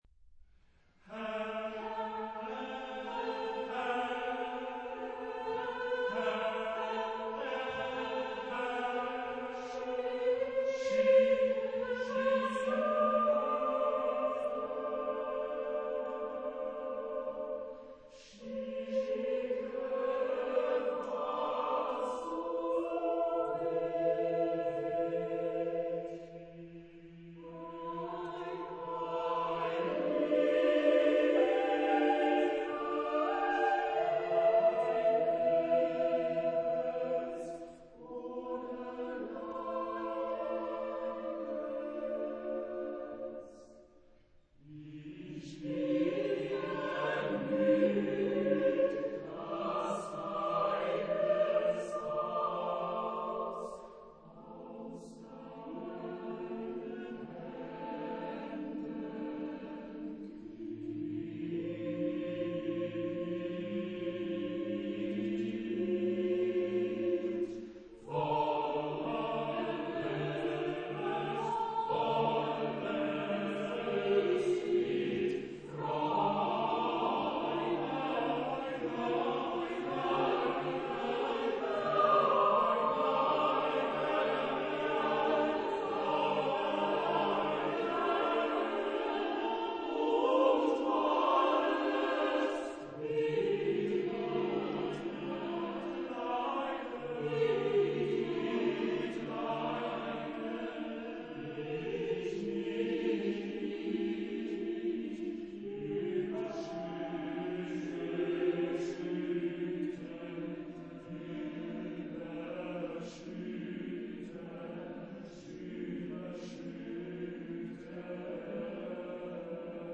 Genre-Style-Forme : Sacré ; Chœur
Caractère de la pièce : suppliant
Type de choeur : SSAATTBB  (8 voix mixtes )